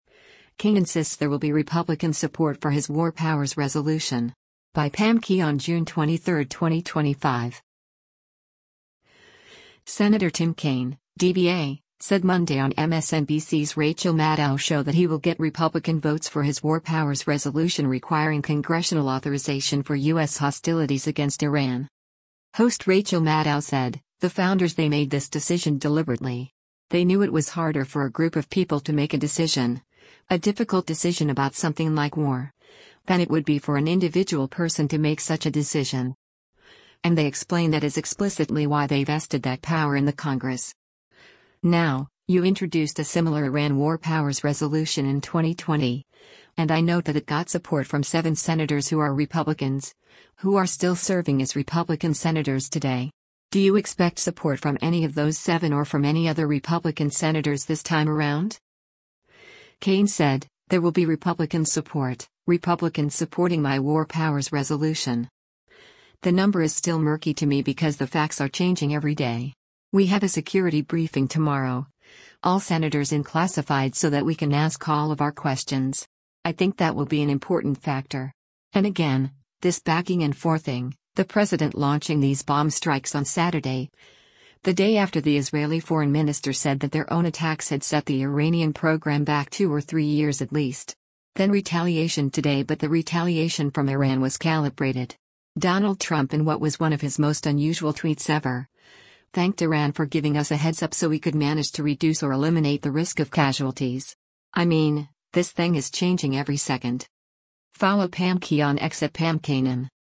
Senator Tim Kaine (D-VA) said Monday on MSNBC’s “Rachel Maddow Show” that he will get Republican votes for his war powers resolution requiring congressional authorization for U.S. hostilities against Iran.